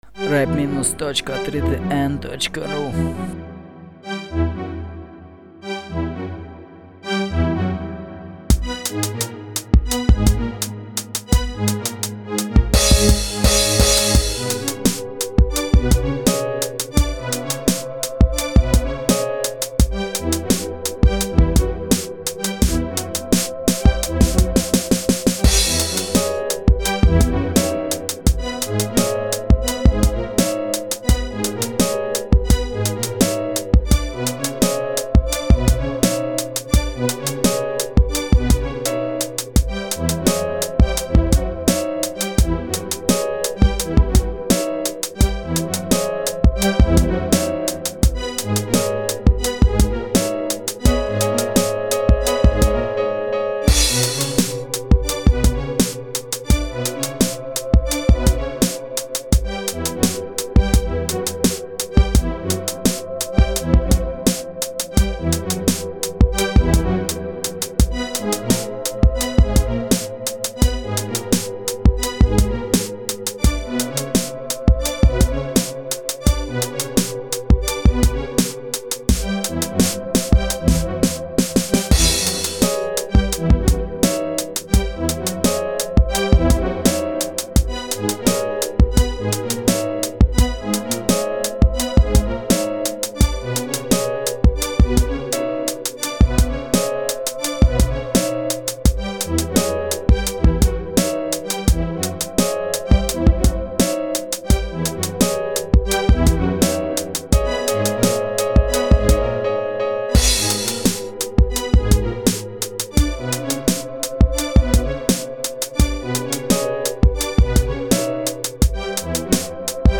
Андерграунд минус, скрипка, пианино
Каналы : 2 (стерео)
голос только в начале, легко вырезать